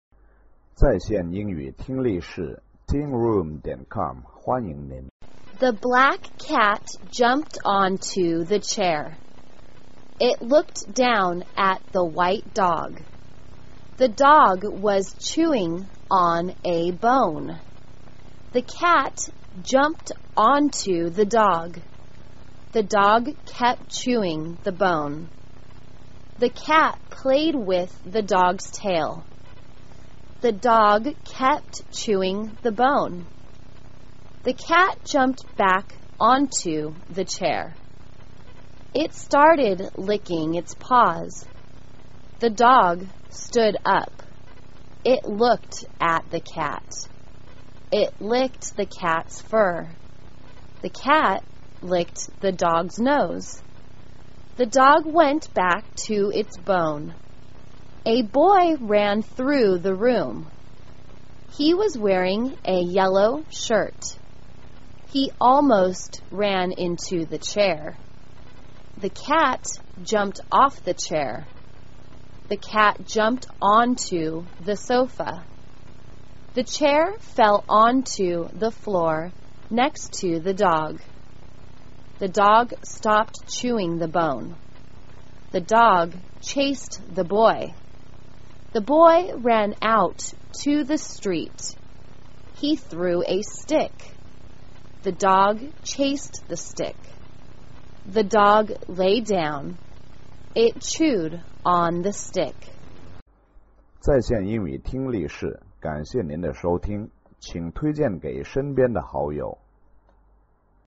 简单慢速英语阅读:A Cat and a Dog 听力文件下载—在线英语听力室